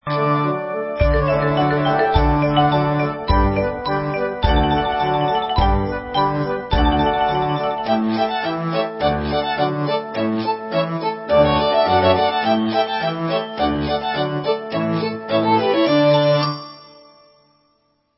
hudba